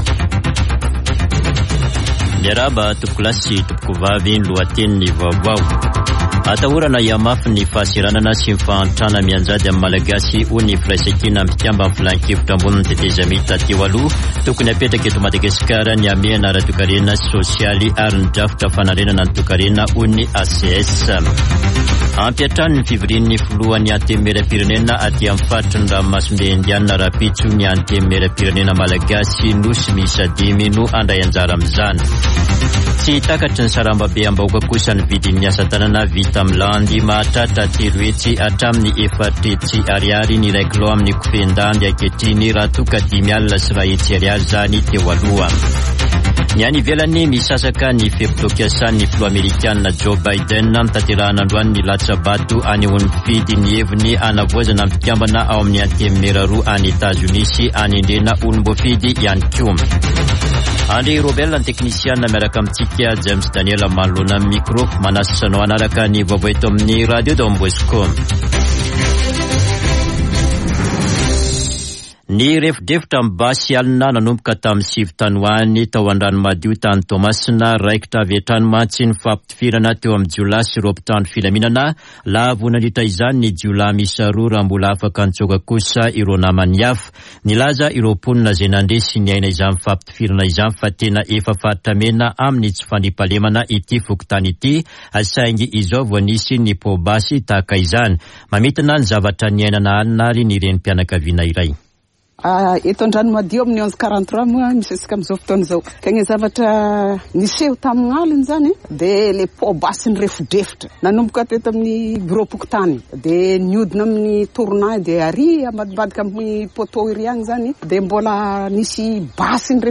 [Vaovao hariva] Talata 8 nôvambra 2022